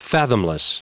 Prononciation du mot : fathomless